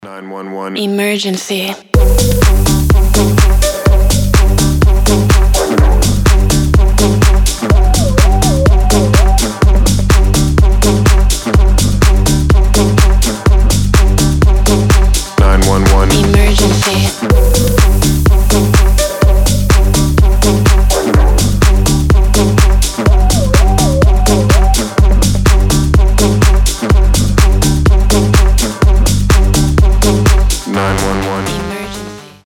• Качество: 320, Stereo
Electronic
EDM
дуэт
Стиль: tech house